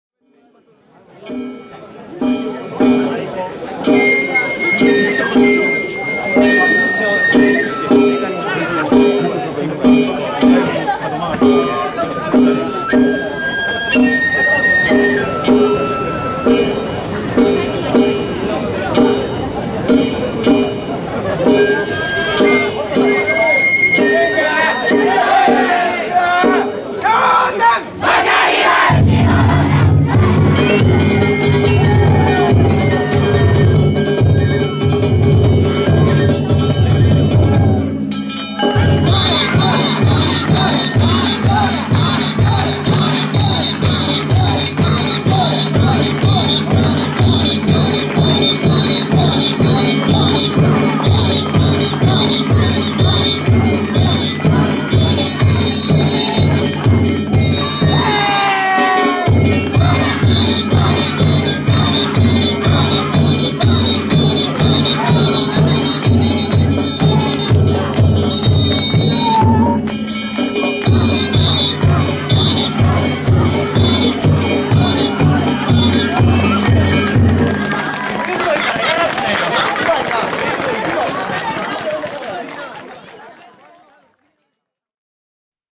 平成２９年７月２日、堺市津久野地区の宮山地車お披露目曳行を見に行ってきました。